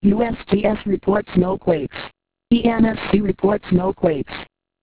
Click here for spoken Earthquake announcements from EMSC This is the webpage for all Earthquake alerts of the last 2 hours.